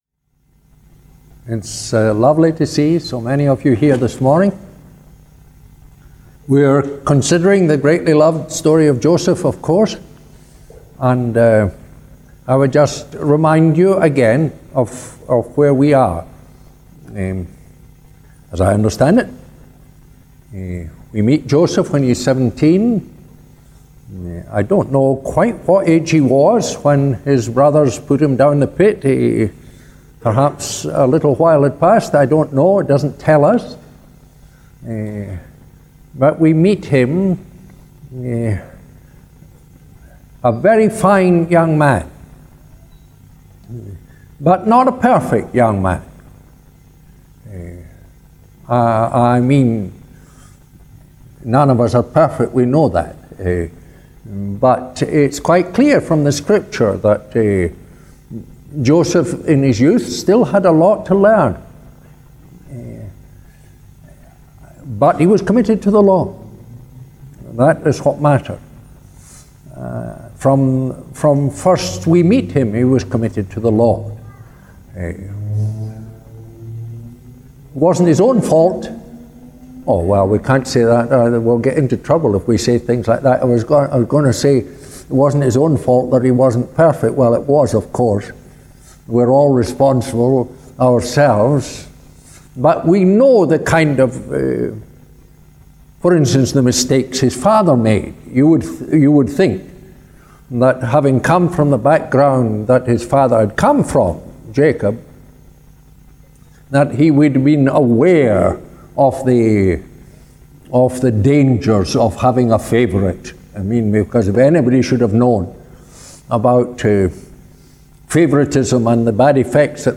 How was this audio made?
Service Type: Family Service Topics: Joseph and Potiphar's Wife